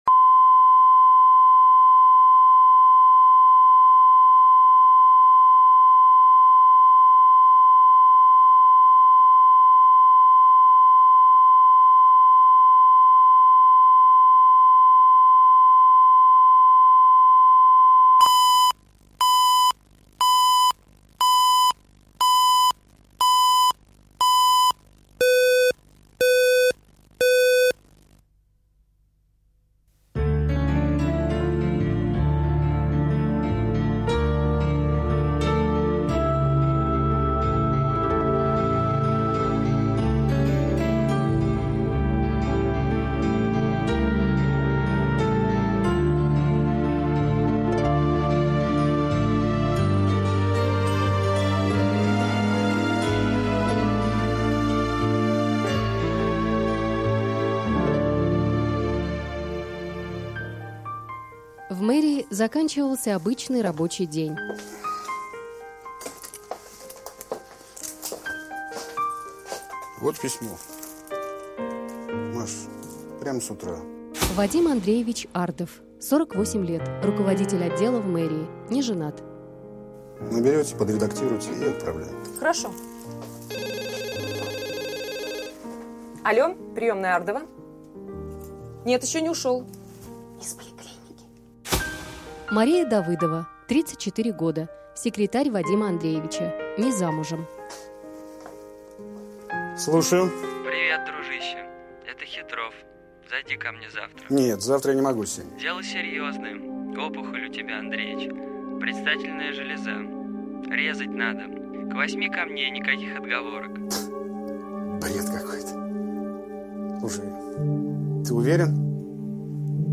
Аудиокнига Грустные мысли | Библиотека аудиокниг